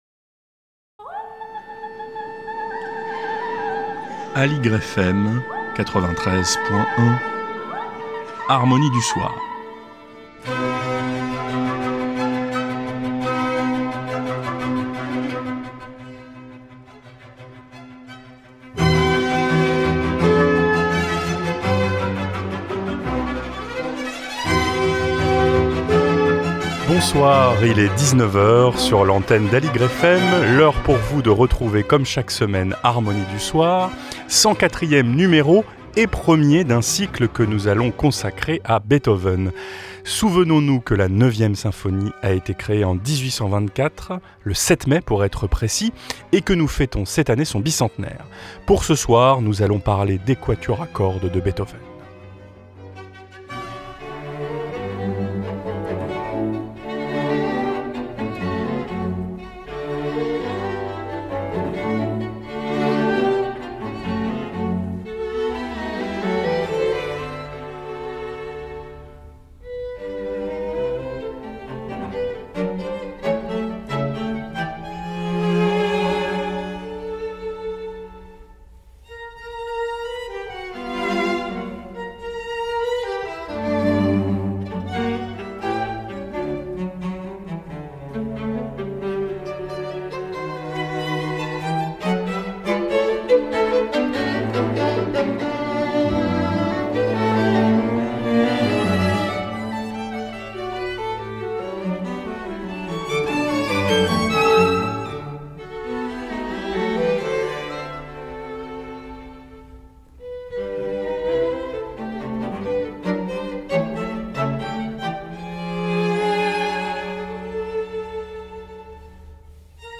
Harmonie du soir # 104 - Beethoven épisode 1 : les quatuors à cordes